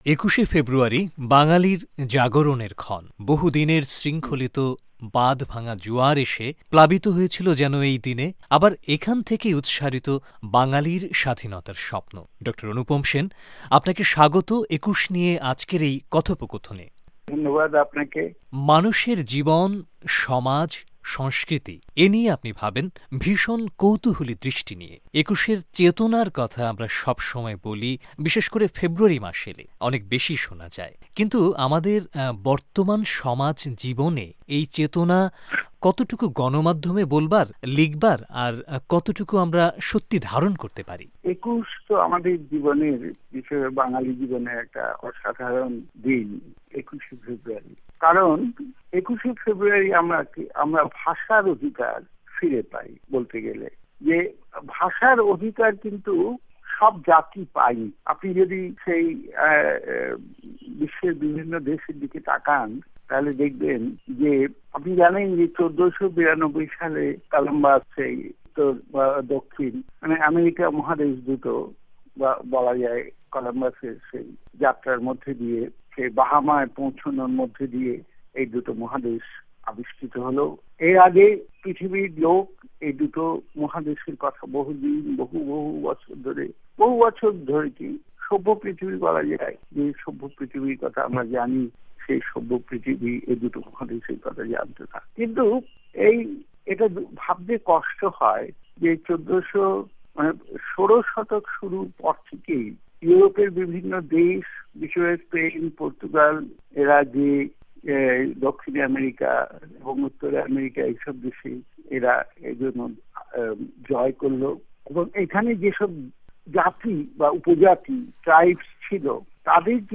কথোপকথন